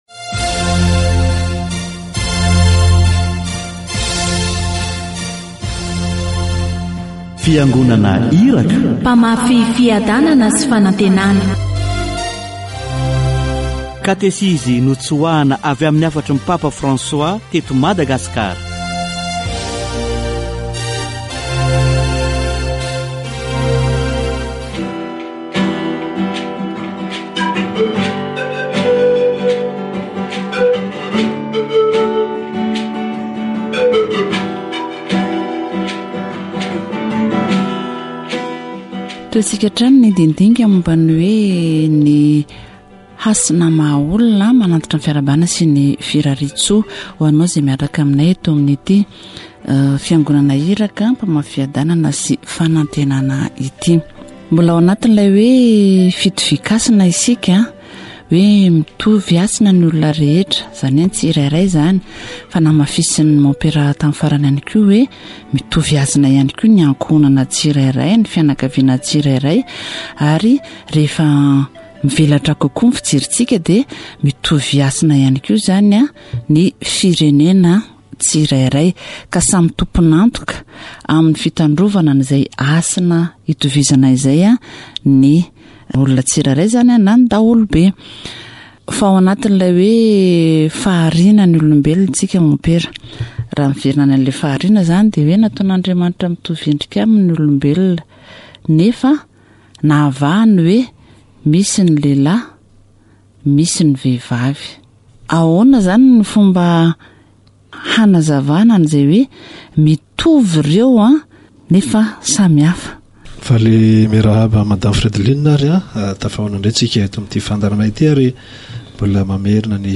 People with disabilities are full human beings: they have rights and responsibilities. Catechesis on human dignity